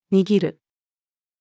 握る-female.mp3